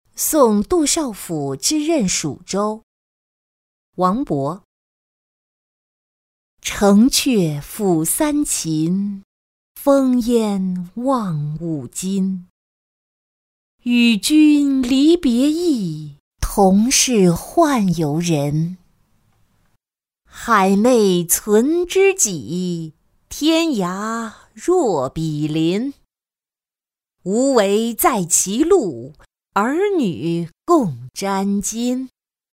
送杜少府之任蜀州-音频朗读